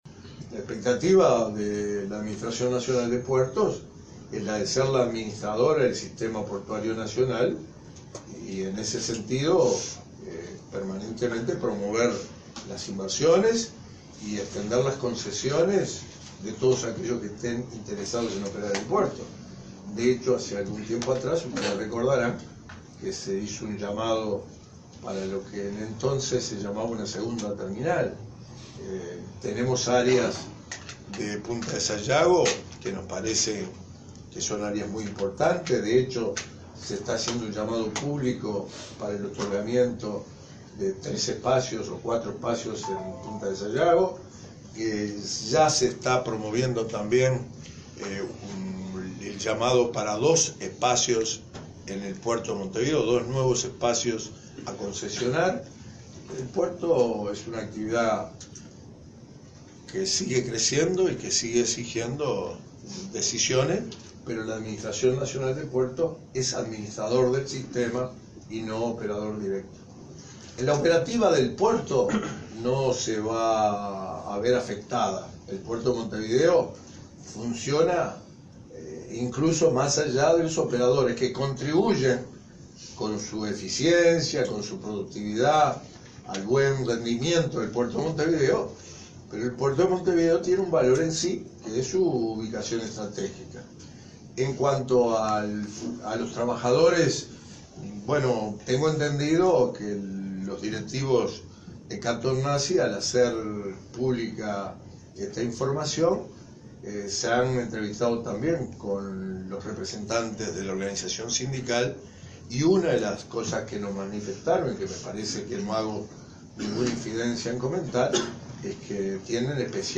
El ministro Víctor Rossi subrayó que se está realizando un llamado público para el otorgamiento de espacios en Puntas de Sayago y otro para concesionar dos nuevos espacios en el puerto de Montevideo. “La ANP es administradora del sistema y no operadora directa”, recordó el jerarca a la prensa, y recalcó que la operativa del puerto no se verá afectada por el retiro de Katoen Natie de sus actividades de la terminal portuaria.